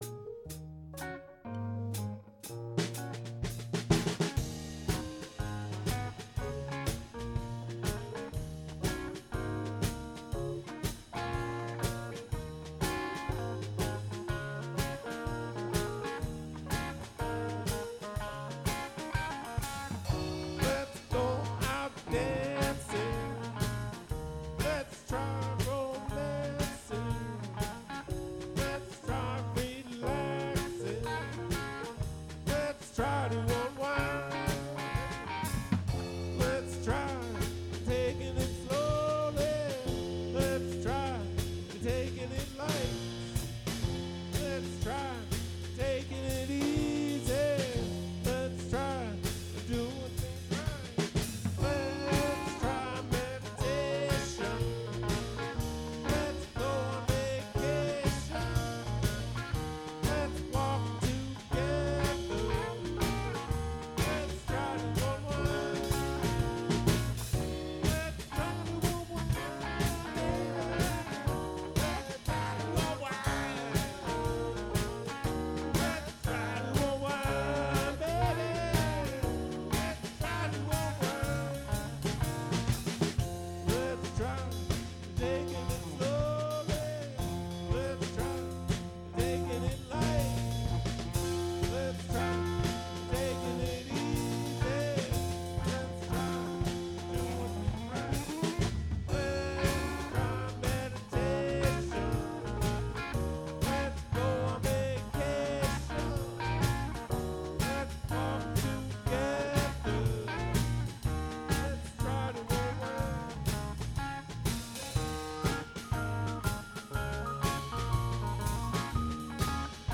R&B
Audio File: Live recording - max taps show (2/14/2026)